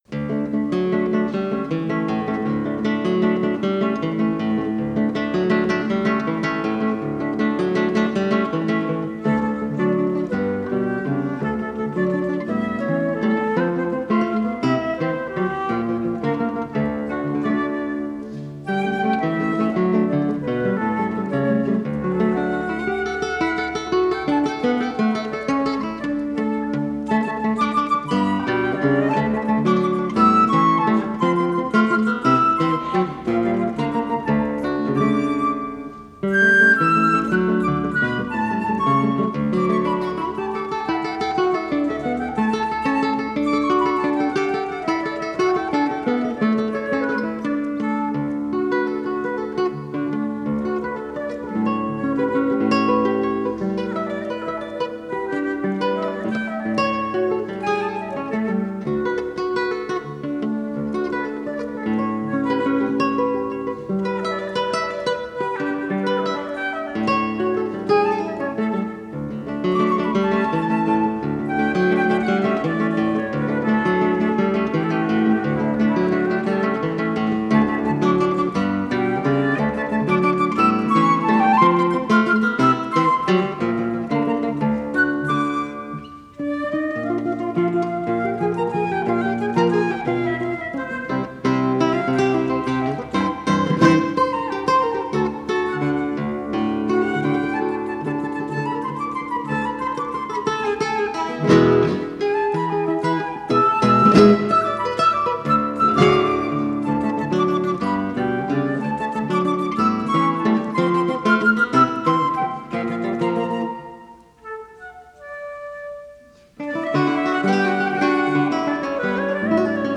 guitar
flute, is the last grouping in their 1984 live concert